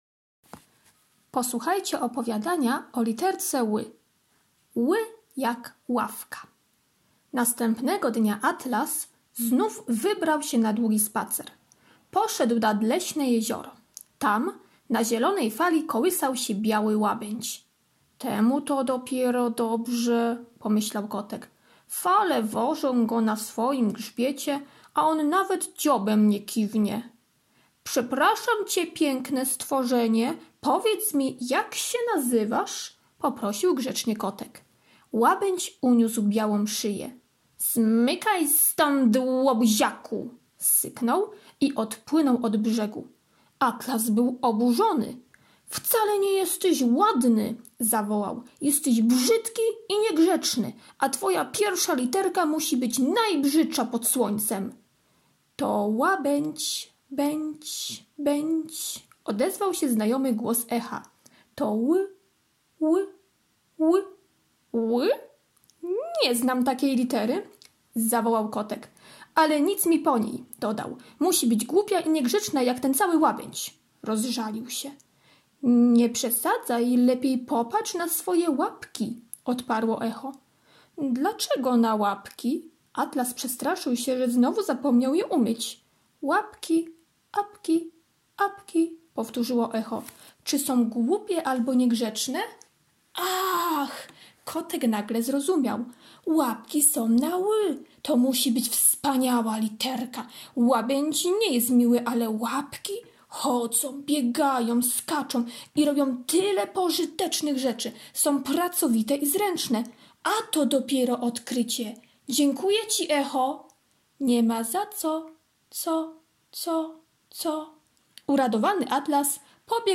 poniedziałek - opowiadanie "Ł jak ławka" [6.75 MB] poniedziałek - karta pracy nr 1 [162.22 kB] poniedziałek - ćw. dla chętnych - kolorowanka z literką Ł [452.12 kB] poniedziałek - ćw. dla chętnych - labirynt z literką Ł, ł [213.71 kB]